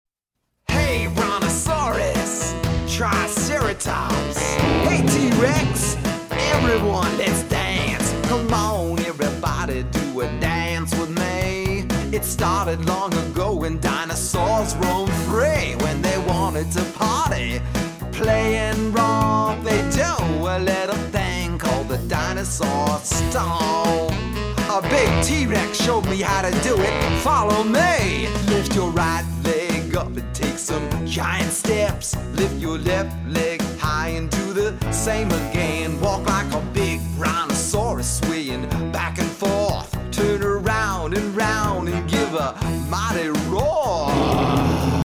a children’s chorus